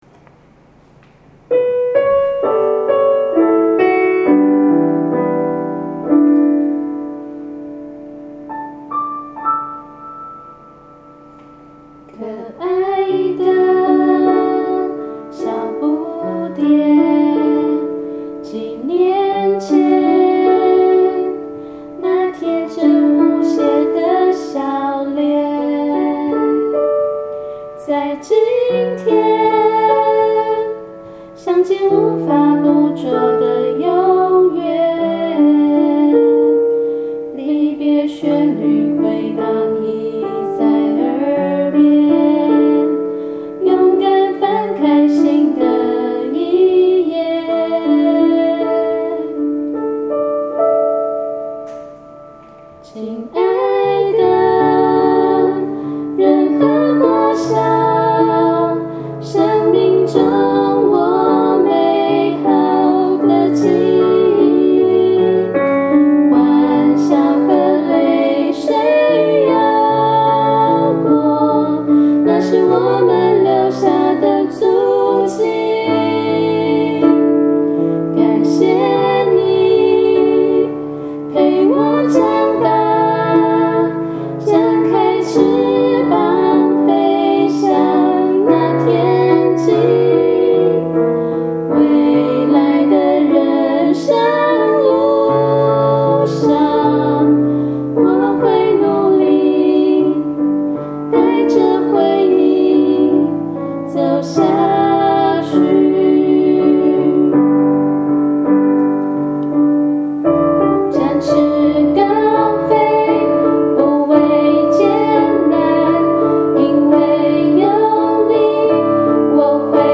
(公告)第七屆畢業歌演唱版本 - 仁和國小學務系統
本歌曲為本校教師專為本校第七屆畢業生而創作，附件檔案提供演唱示範及聆聽。